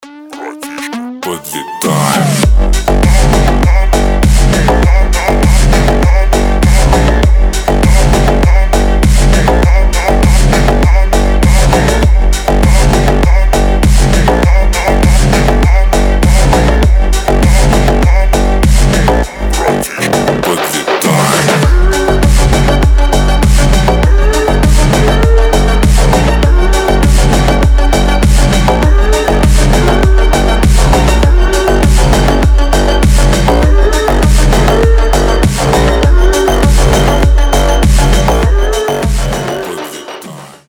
Клубные рингтоны Добавлен